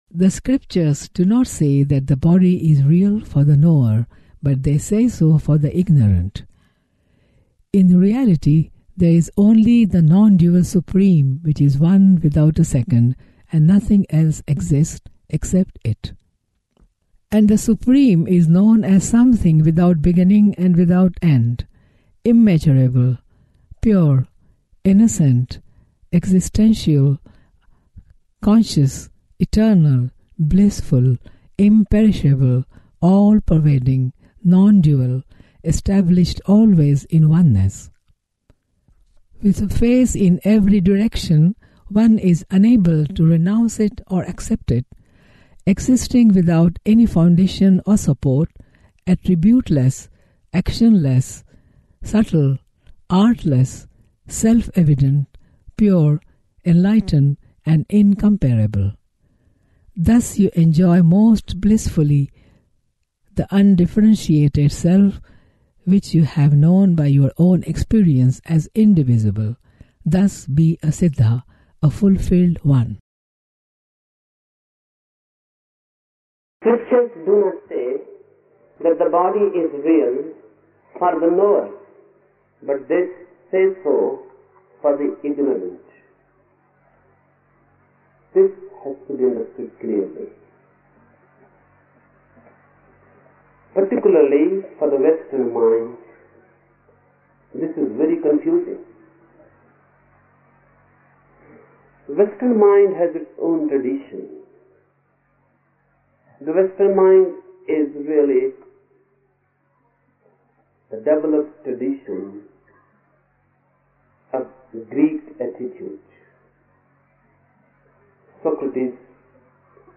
Each program has two parts, Listening Meditation (Osho discourse) and Satsang Meditation.
The Osho discourses in the listening meditations in this module are mostly from talks in which Osho was speaking on the Upanishads and were given at various meditation camps held in India between 1972 and 1974.